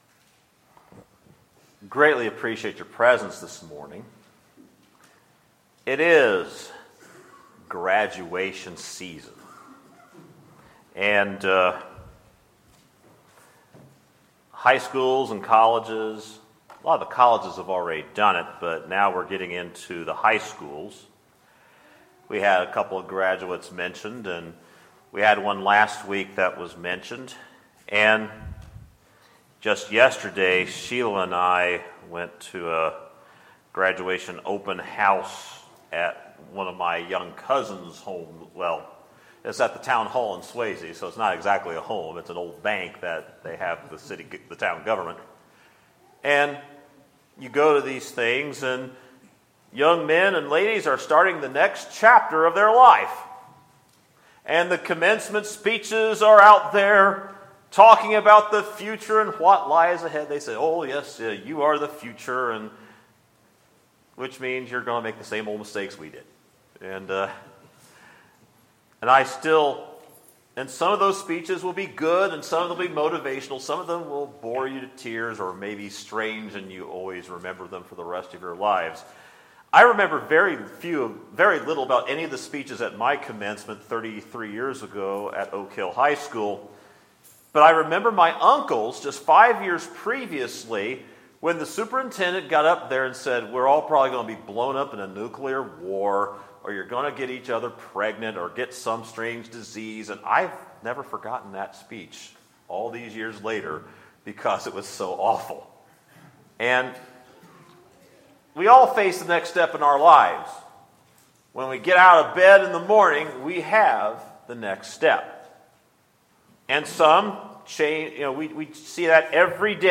Sermons, June 4, 2017